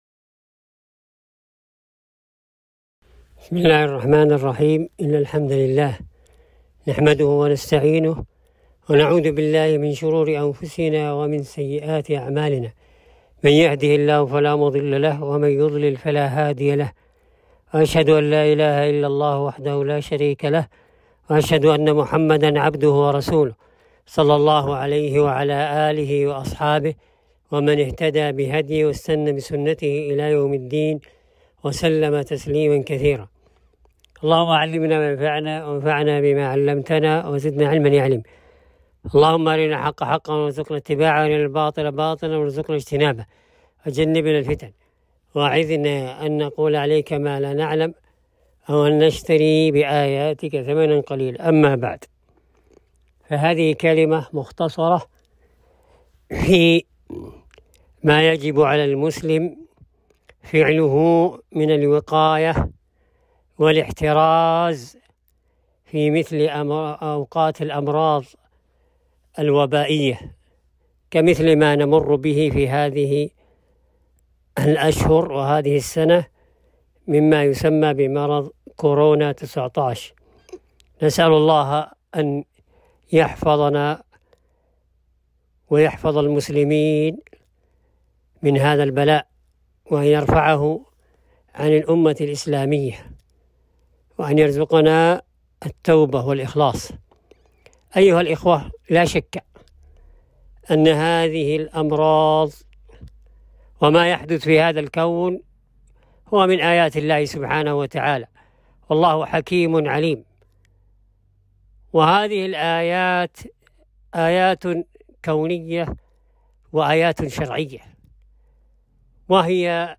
كلمة - الوباء والرجوع الى الله واخذ أسباب الوقاية منه